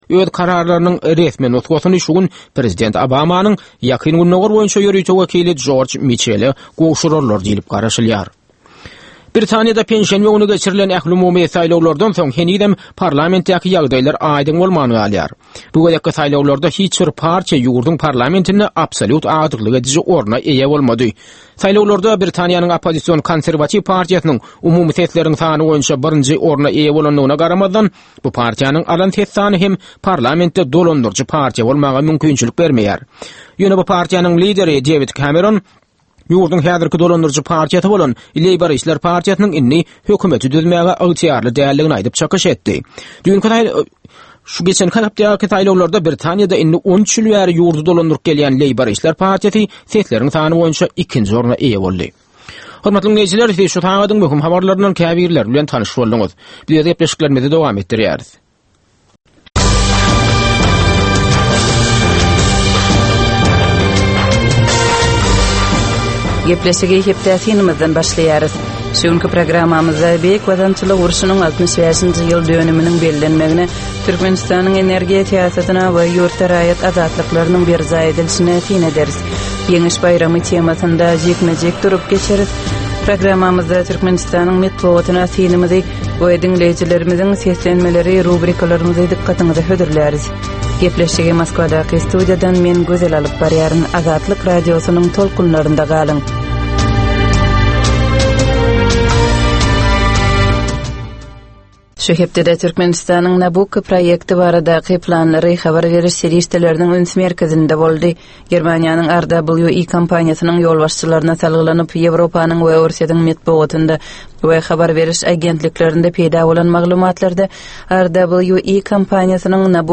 Tutuş geçen bir hepdäniň dowamynda Türkmenistanda we halkara arenasynda bolup geçen möhüm wakalara syn. 25 minutlyk bu ýörite programmanyň dowamynda hepdäniň möhüm wakalary barada gysga synlar, analizler, makalalar, reportažlar, söhbetdeşlikler we kommentariýalar berilýär.